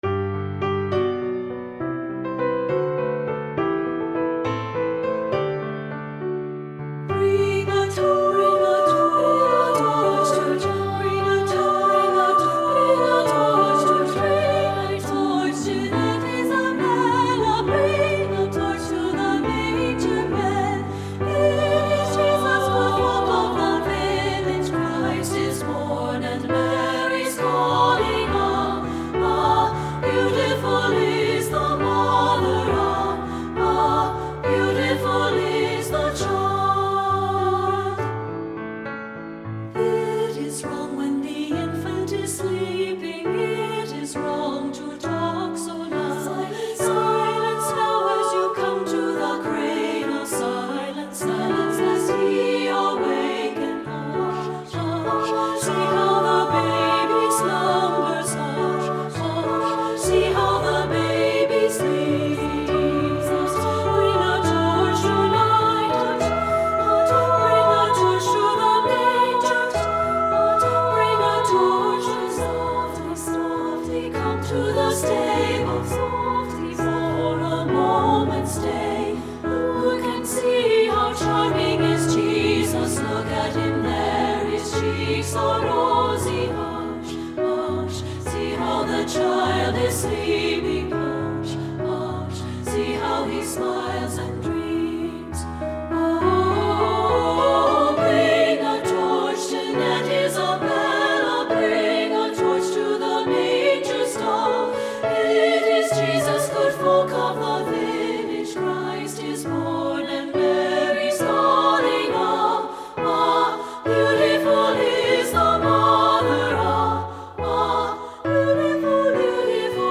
French Carol
• Soprano 1
• Soprano 2
• Alto
• Piano (opt.)
Studio Recording
Ensemble: Treble Chorus